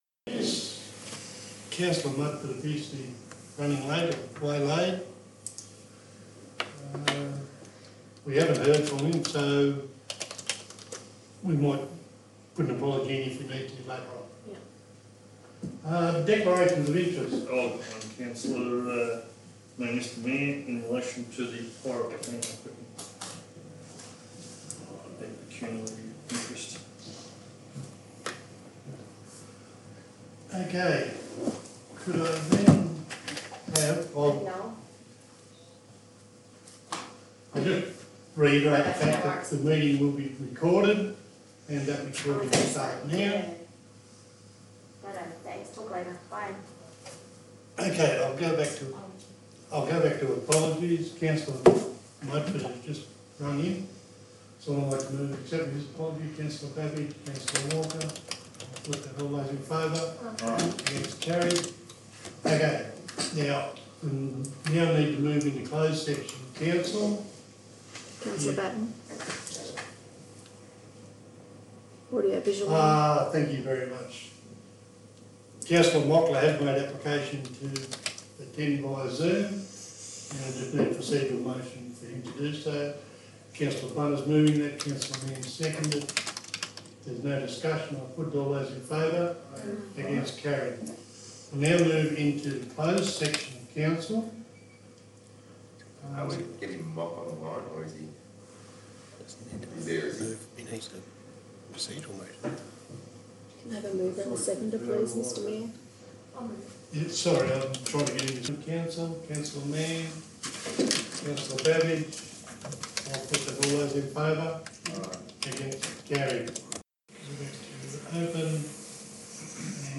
27 September 2022 Extraordinary Meeting - Gilgandra Shire Council